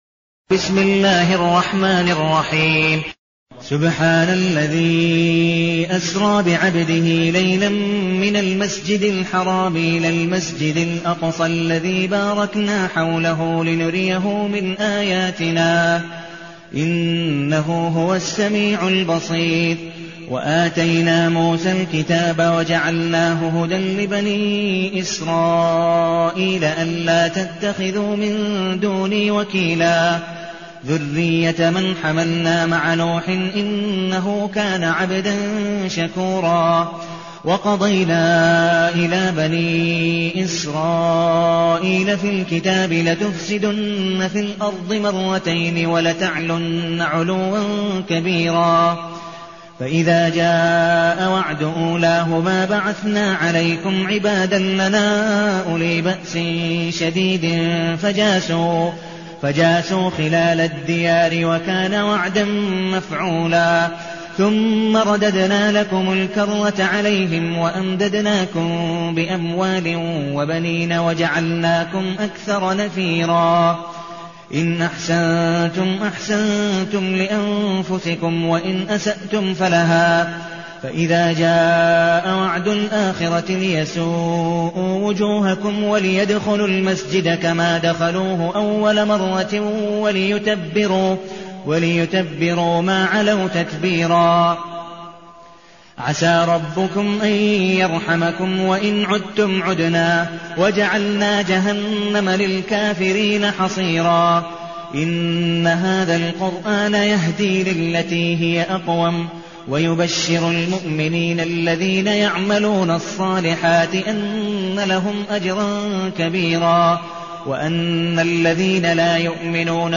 المكان: المسجد النبوي الشيخ